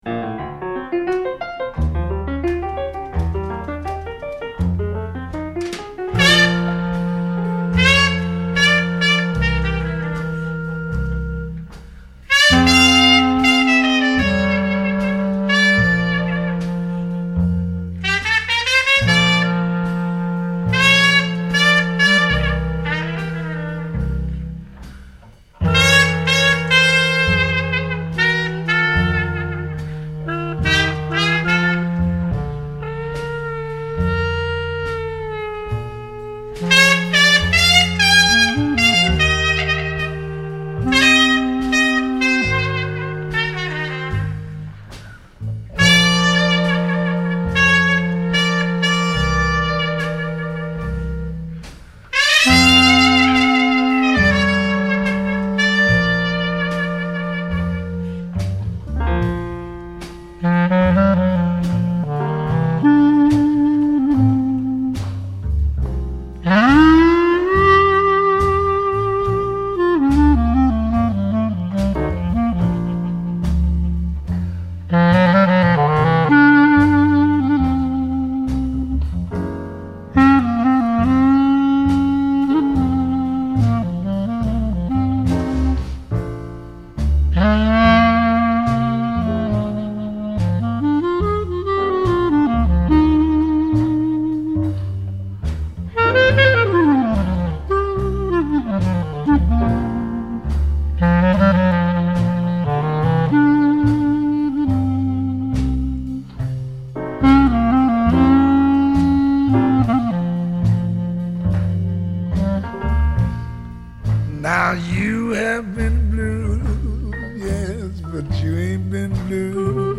Dixieland (1917-1920)
Συνήθως μια μπάντα σε στυλ «Ντίξιλαντ» (Dixieland) περιλάμβανε ένα «μελωδικό τμήμα» (melody section) που αποτελούνταν από τρομπέτα/κορνέτα, κλαρινέτο, τρομπόνι και περιστασιακά σαξόφωνο.
Το «ρυθμικό τμήμα» (rhythm section) περιλάμβανε μπάντζο, πιάνο, τύμπανα, μπάσο ή τούμπα. Η μουσική είχε ένα σταθερό, συχνά συγκοπτόμενο ρυθμό 4/4 και πολλές φορές έπαιζαν τρίηχα.